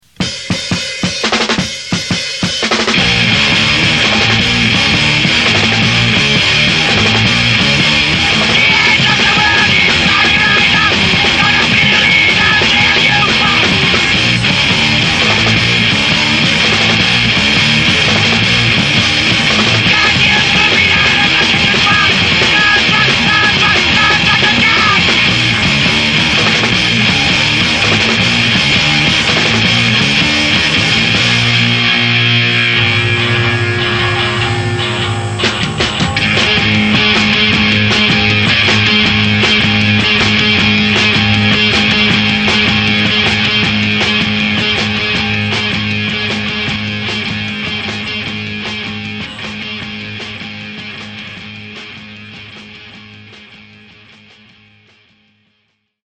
Punkrockhcwhatever.